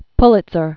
(plĭt-sər, pylĭt-), Joseph 1847-1911.